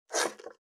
469,切る,包丁,厨房,台所,野菜切る,咀嚼音,ナイフ,調理音,まな板の上,料理,
効果音